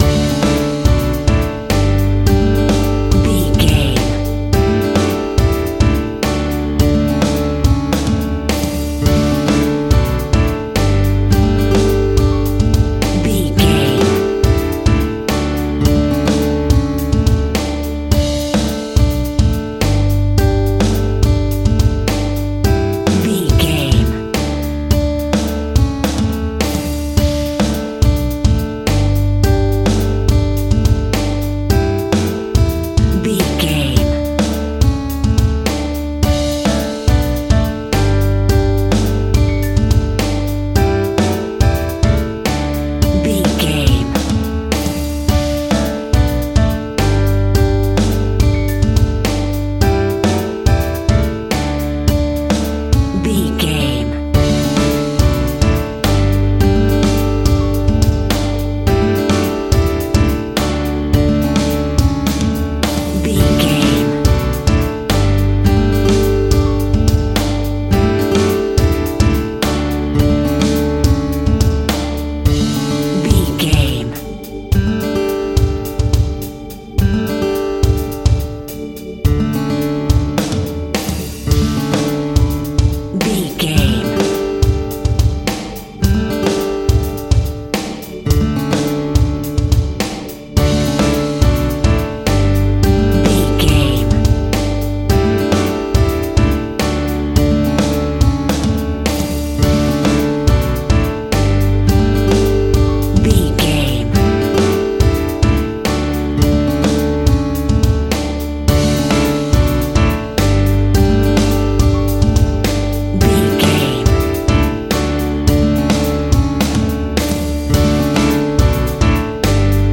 TV Pop Music.
Ionian/Major
D
pop rock
indie pop
fun
energetic
uplifting
synths
drums
bass
guitar
piano